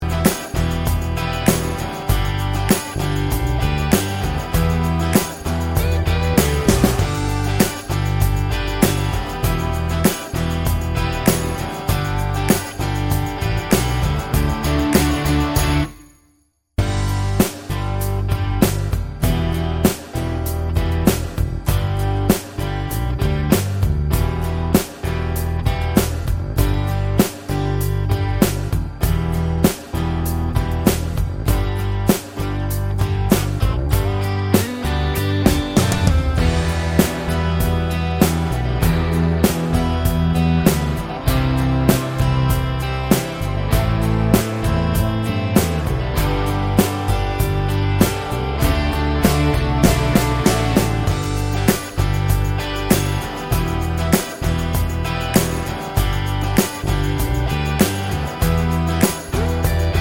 With Full Intro Pop (2010s) 5:27 Buy £1.50